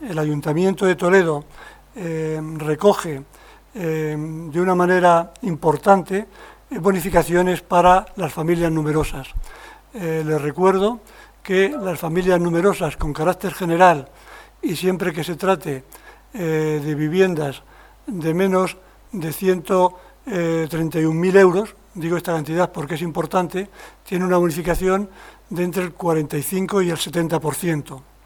El concejal de Hacienda, José Pablo Sabrido, ha informado en rueda de prensa del proyecto de modificación de ordenanzas fiscales y precios públicos para el año 2022 que se aprobará este miércoles como trámite inicial en la Junta de Gobierno Local.
AUDIOS. José Pablo Sabrido, concejal de Hacienda
jose-pablo-sabrido_bonificacion-ibi-familias-numerosas.mp3